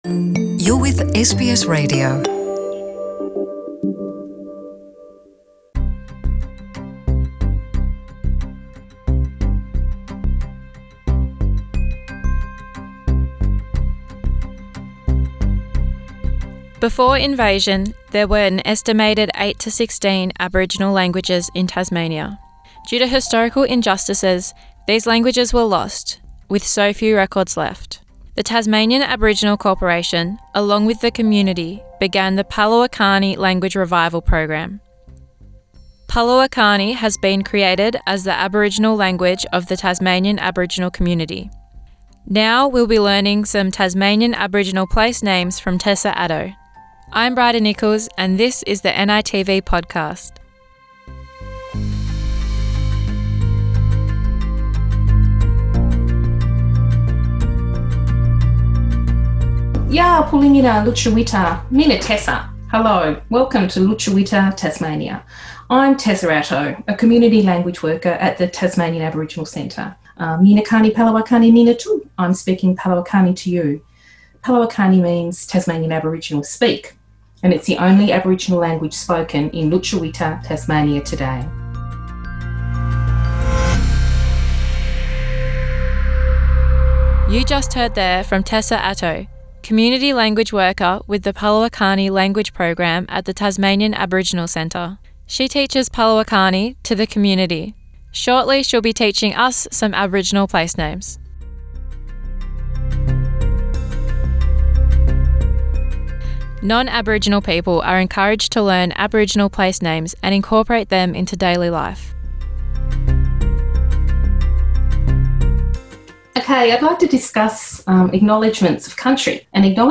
In this second and final episode of the palawa kani language podcast special we will learn some of the palawa kani language and we will learn some language of various place names from lutruwita (Tasmania). Interview